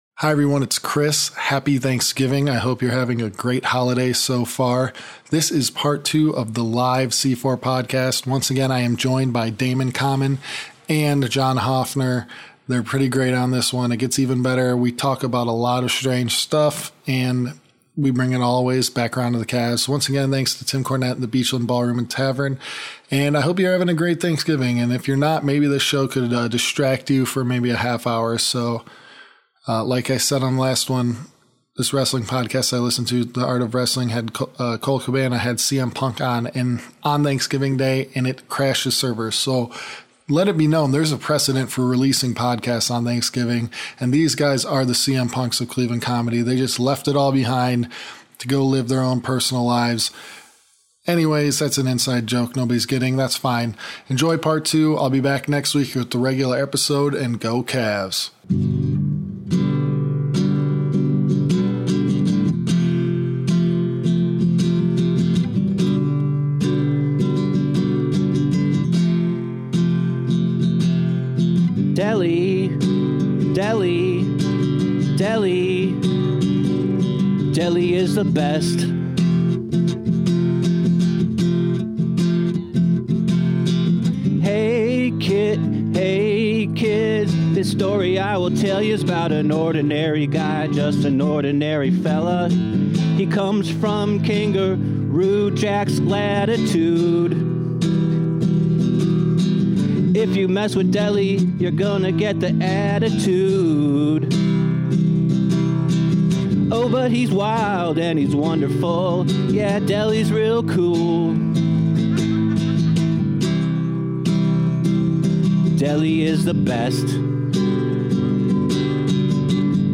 Part two of Tuesday’s live episode!